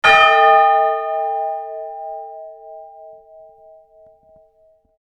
Cast Iron Bells